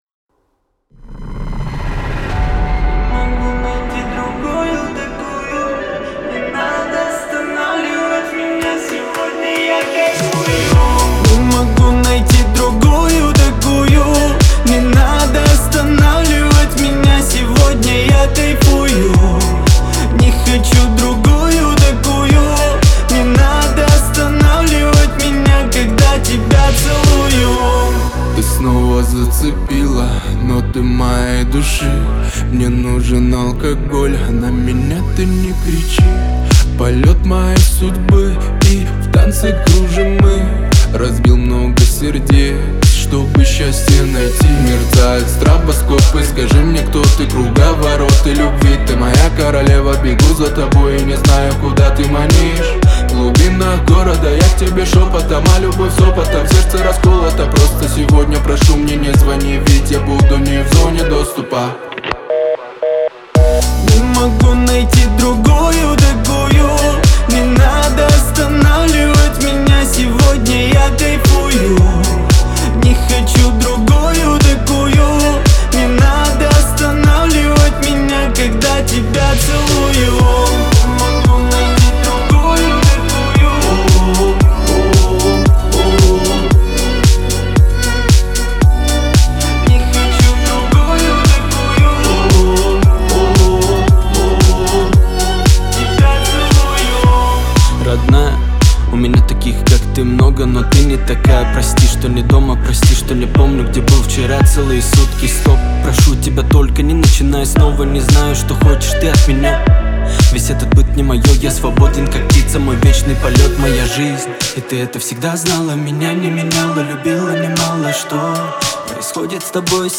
это энергичная и зажигательная композиция в жанре хип-хоп
позитивное и жизнеутверждающее